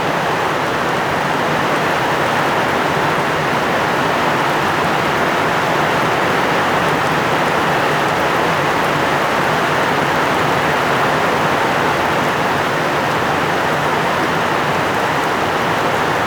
Heavy Rain Ambient Loop 1.wav